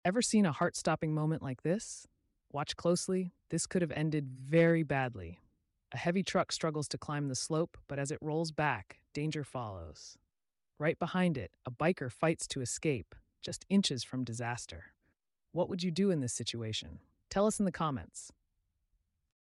Truck Struggles to Climb – sound effects free download